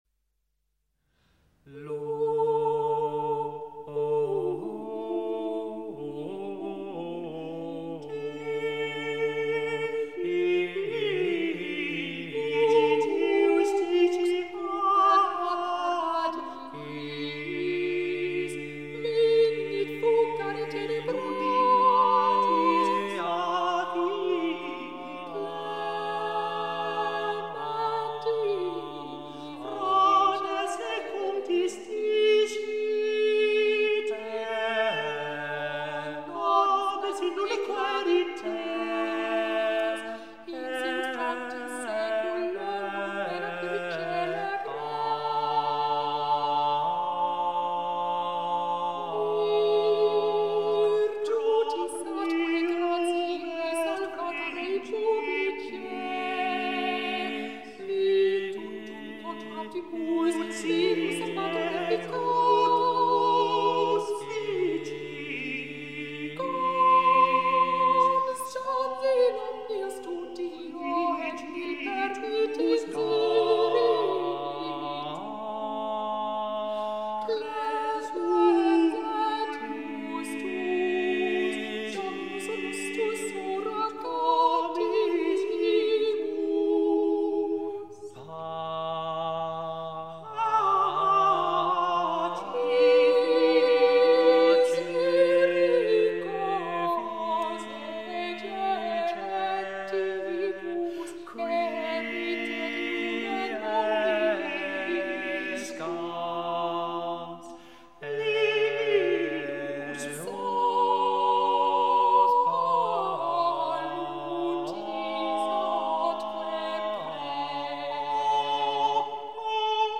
Lux purpurata - Diligite justiciam - Mottetto — Laurea Magistrale in Culture e Tradizioni del Medioevo e del Rinascimento